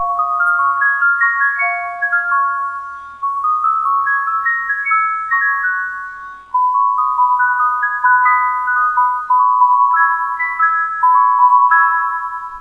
ここにまとめてオルゴールを録音してWAVEファイルにしてみました。
いまいちの音質です。
オルゴール]